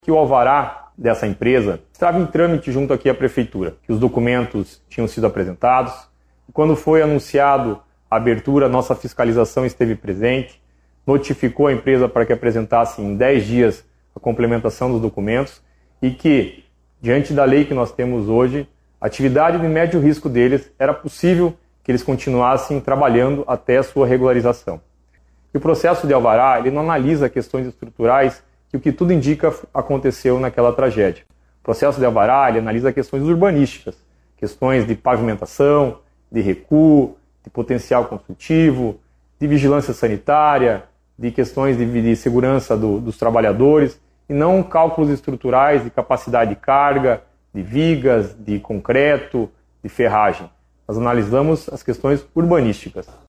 O prefeito de Pontal do Paraná, Rudão Gimenes, afirmou que a prefeitura já prestou os esclarecimentos necessários à polícia e que o processo de alvará emitido pelo executivo não faz a fiscalização de questões estruturais.